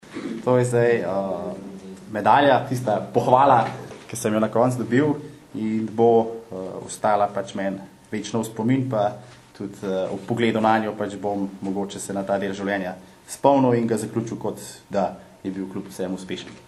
izjava_zankosir1.mp3 (407kB)